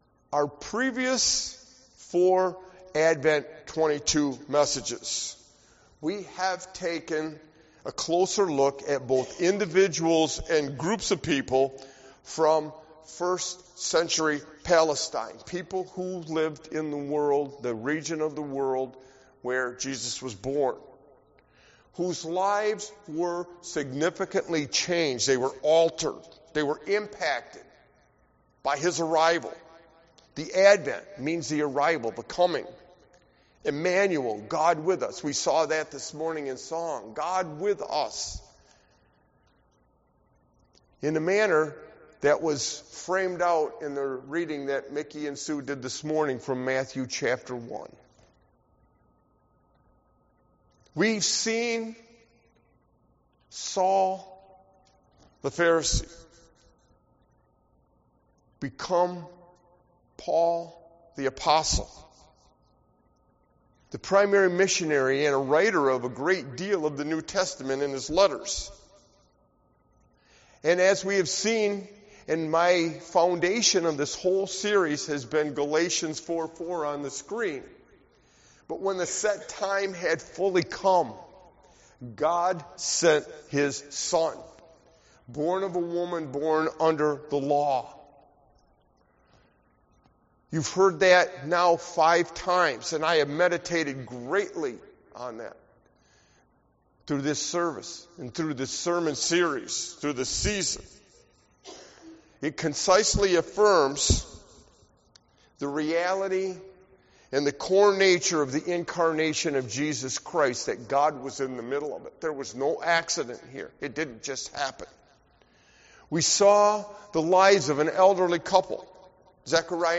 Sermon Advent 22 Why we Celebrate Christmas wk 5